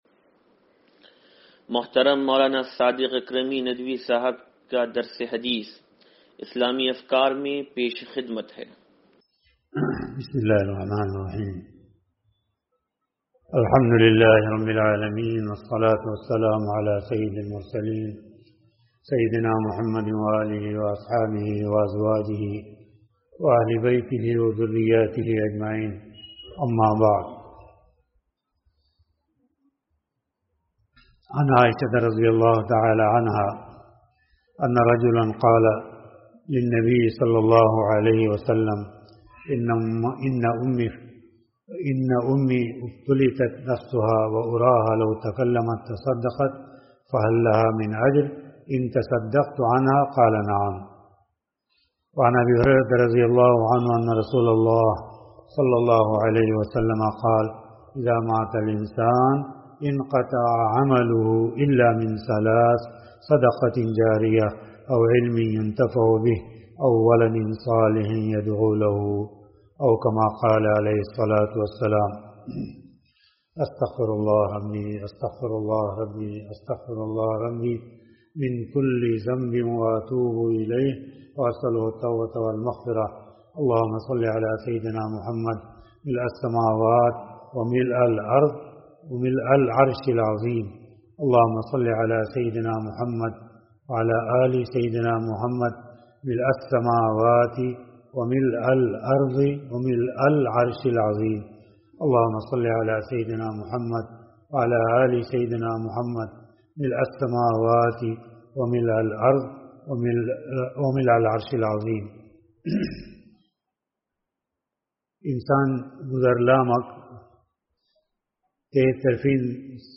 درس حدیث نمبر 0744